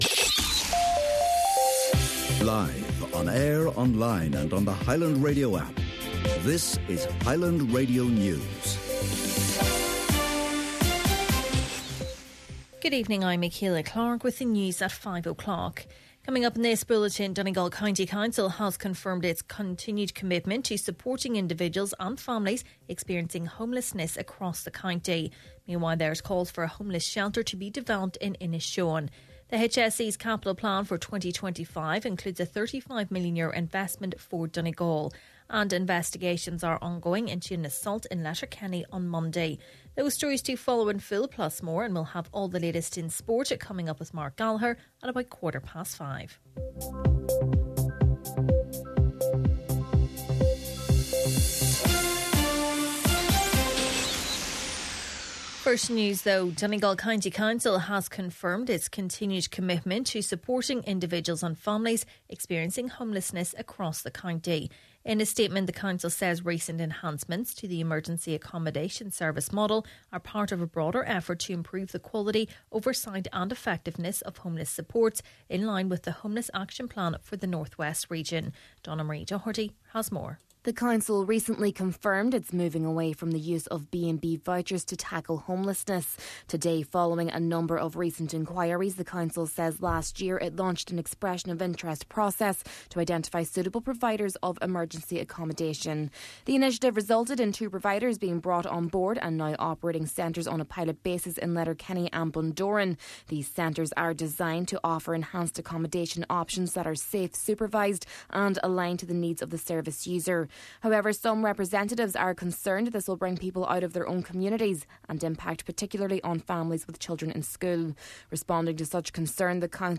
Main Evening News, Sport and Obituaries – Wednesday, April 9th